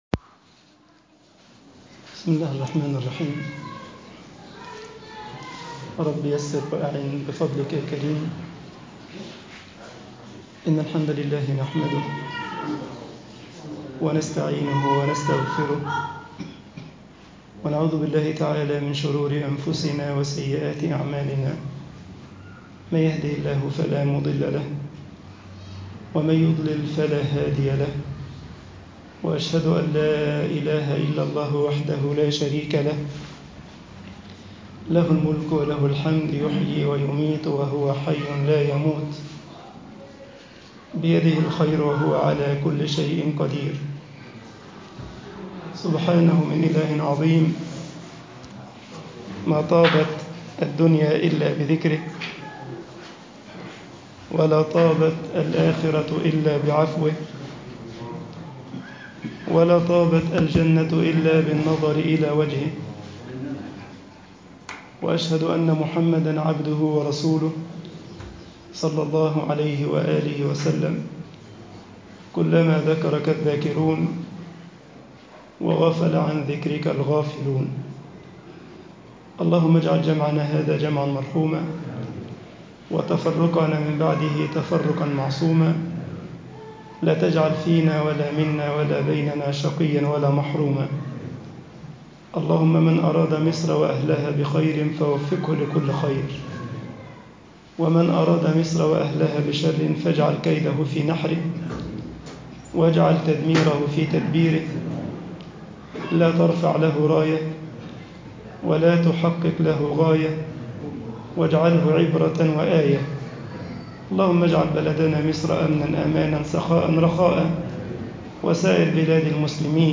رحمات بين يدي سورة الحجرات آية 1 درس 3